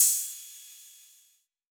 Crashes & Cymbals
Metro Crash 6 .wav